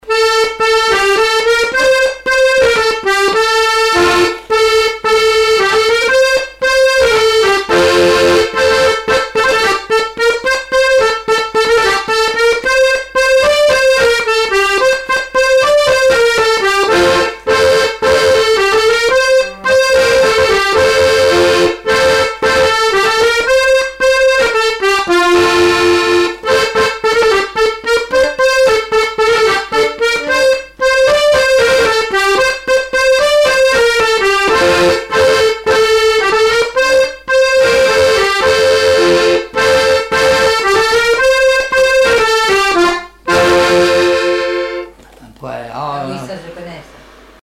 danse : marche
Répertoire du musicien sur accordéon chromatique
Pièce musicale inédite